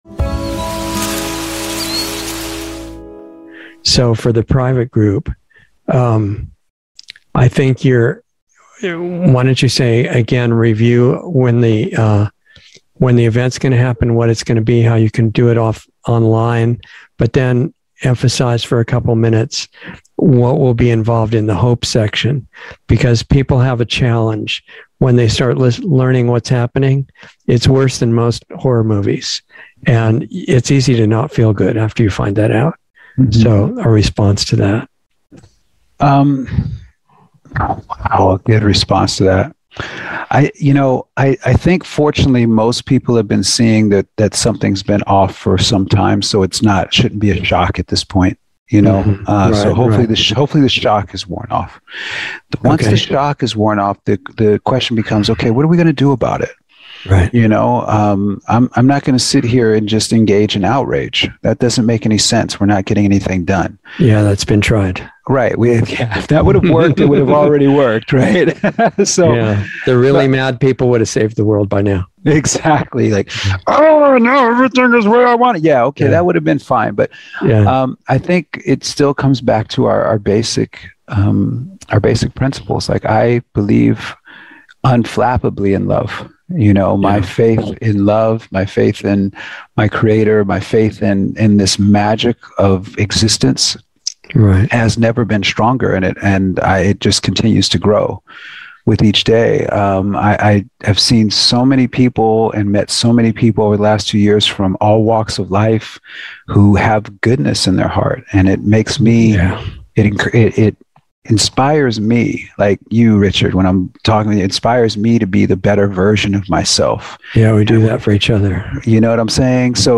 Insider Interview 5/5/22